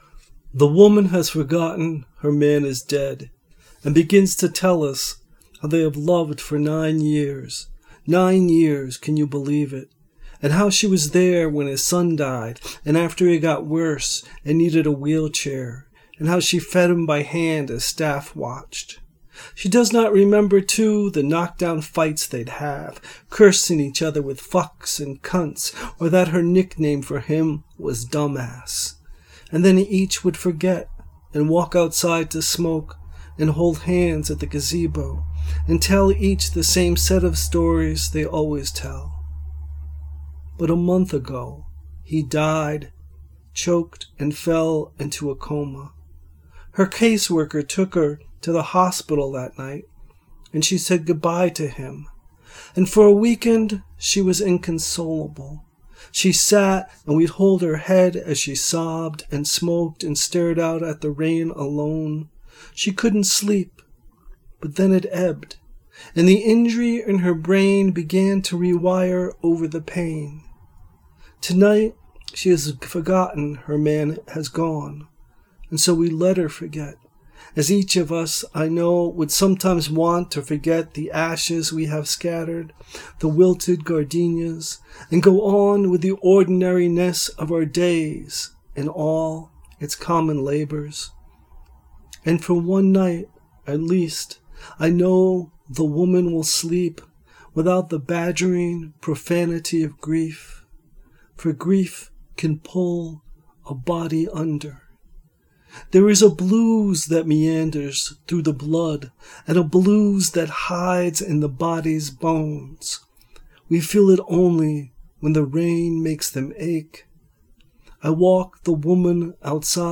Press Play to hear the author read their piece.